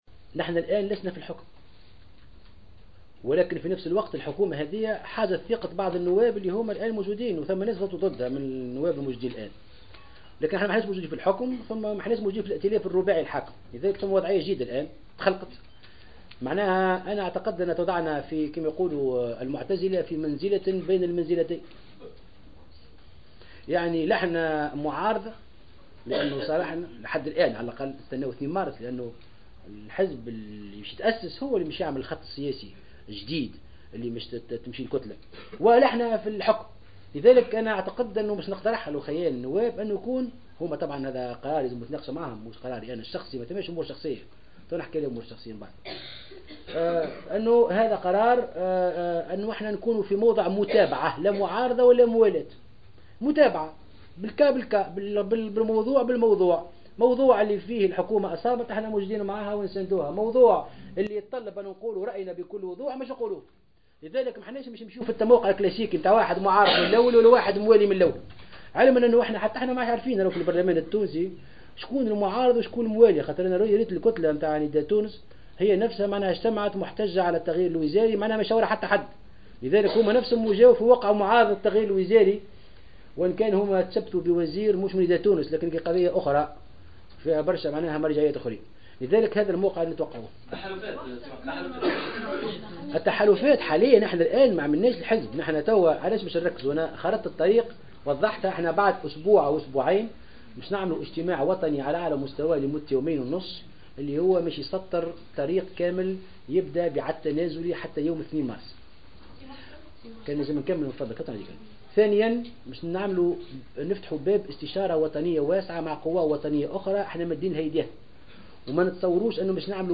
وتحدث مرزوق في تصريحاته للإعلاميين عقب الاجتماع عن الخطوط العريضة لمبادرته الجديدة ومبادئها وخلفياتها الفكرية والإيديولوجية، كما بيّن تموقعها في المشهد السياسي الجديد.